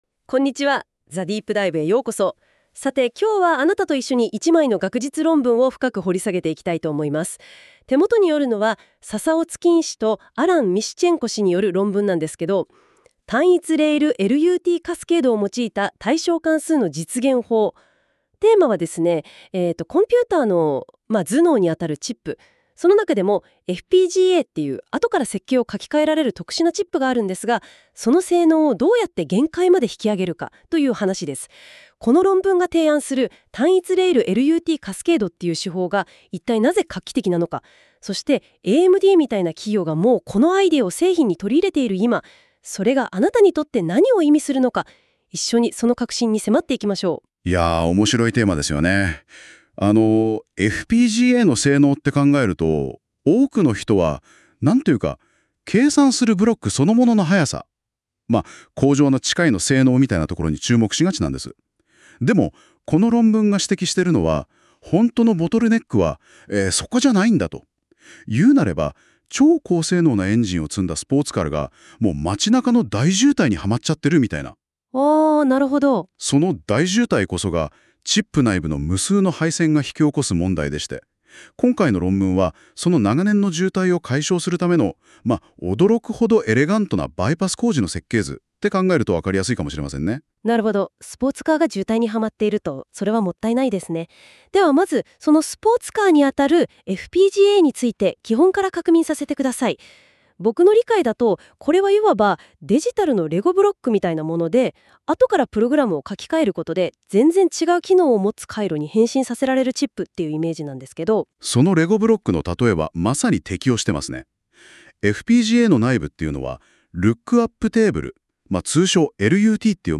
NotebookLM_Single_rail_JP.m4a